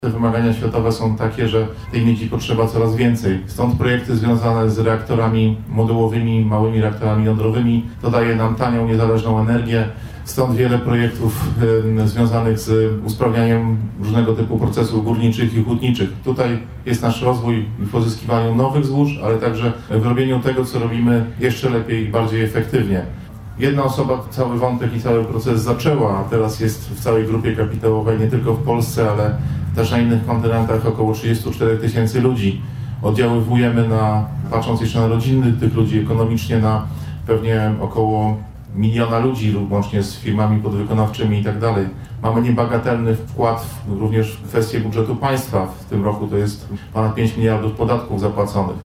Z tej okazji przy pomniku Jana Wyżykowskiego w Lubinie odbyły się uroczystości upamiętniające tamto wydarzenie.